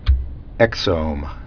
(ĕksōm)